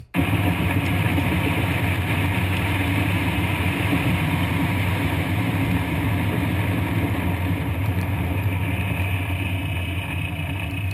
Vibration throughout the whole vehicle, Rattling sound
The audio clip above is what loose lugnuts sound like when driving a vehicle.
Loose-Lug-Nut-Sound.mp3